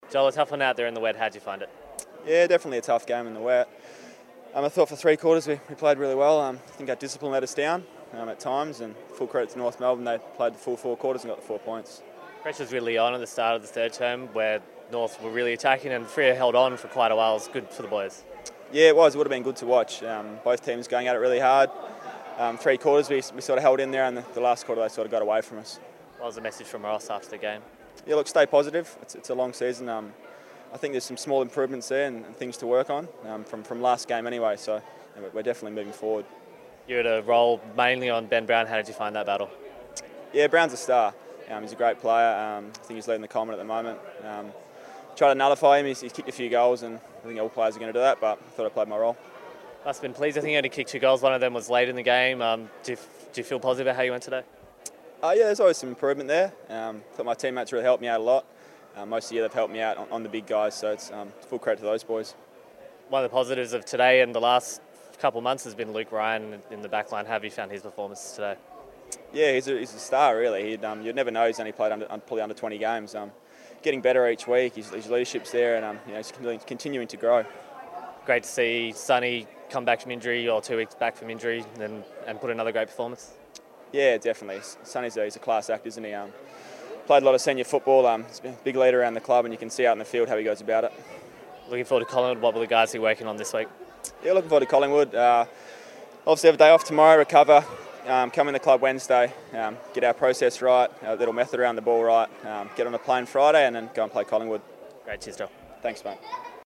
Joel Hamling chats to Docker TV after the Round 10 clash against North Melbourne.